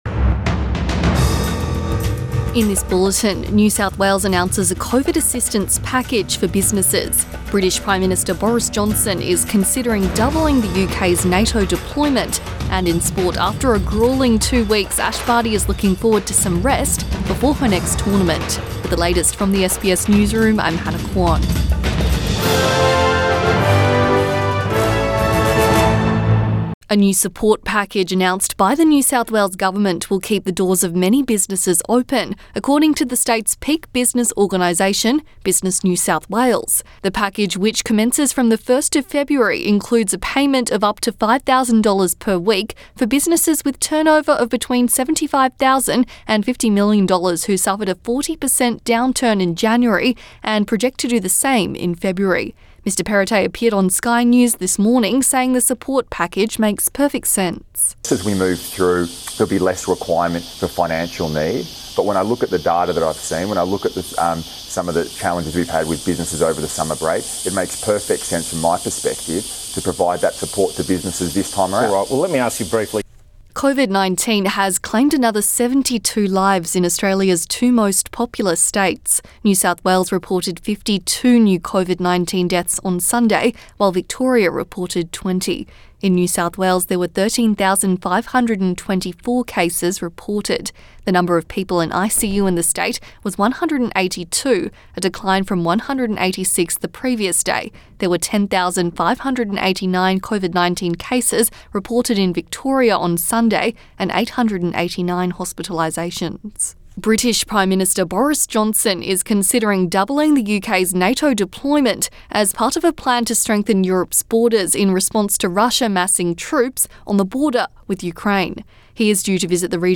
Midday bulletin 30 January 2022